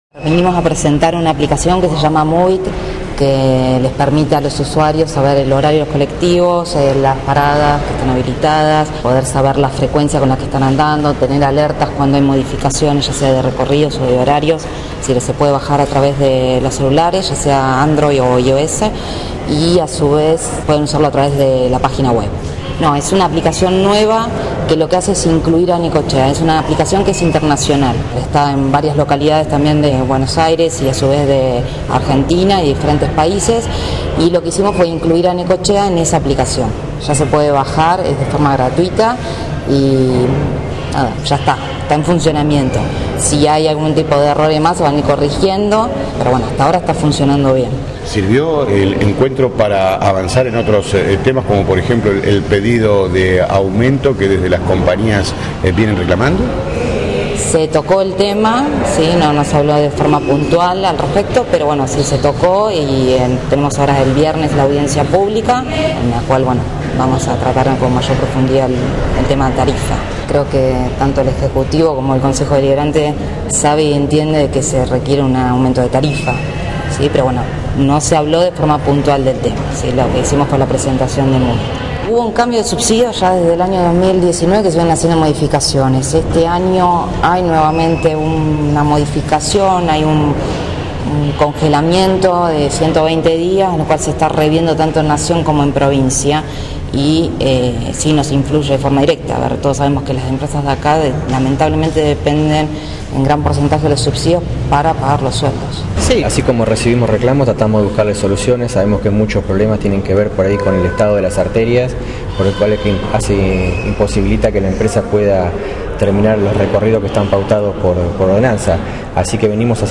El Intendente Municipal, el diputado Martín Domínguez Yelpo y autoridades de la Compañía de Transportes Necochea SA, presentaron la herramienta internacional Moovit ante la prensa.